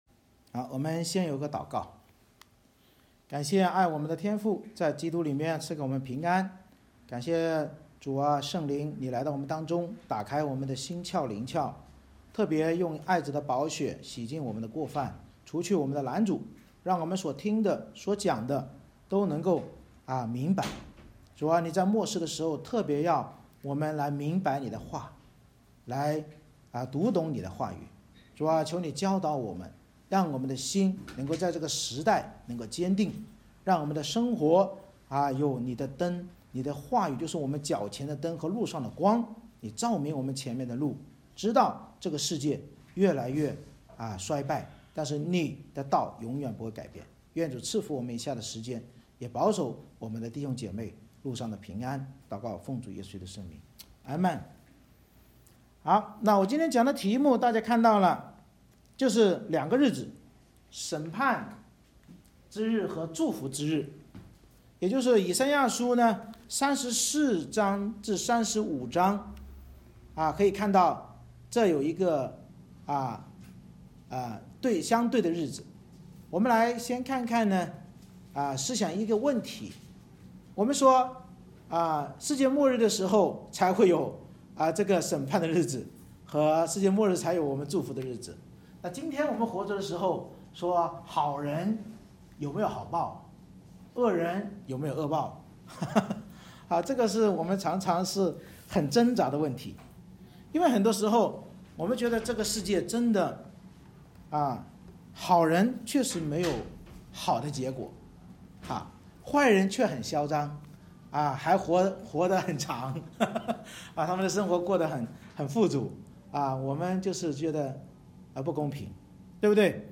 以赛亚书Isaiah34:1-35:10 Service Type: 主日崇拜 神藉着先知预言世界末日的审判与祝福之日，安慰我们：那敌对神及其子民的普世之敌将受到报应，而信靠弥赛亚的赎民必进入千禧国蒙福。